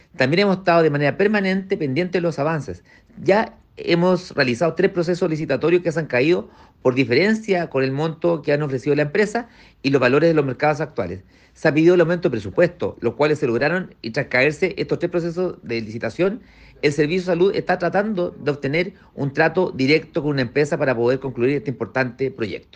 Luego de tres procesos de licitación fallidos, se busca avanzar en un trato directo, comentó el alcalde (s) de la capital regional del Bío Bío, Aldo Mardones.